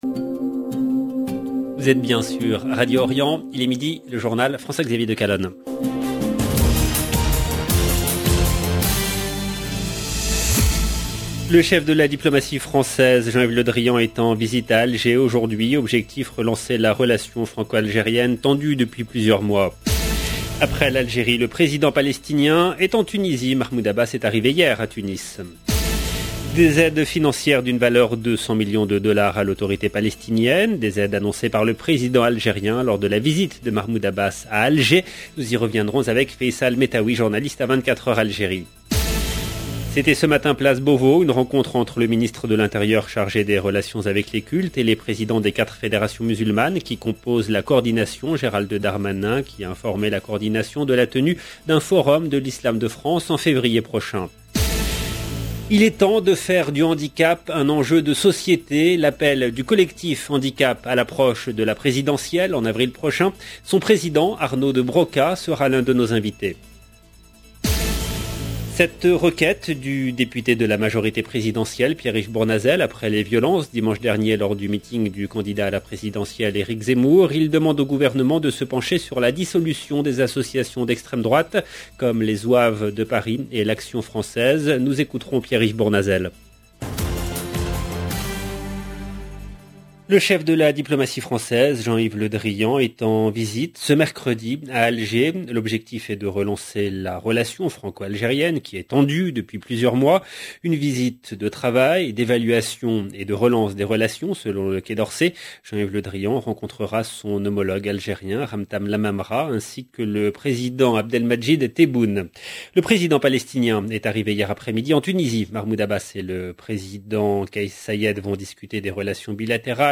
LE JOURNAL EN LANGUE FRANCAISE DE MIDI DU 8/12/21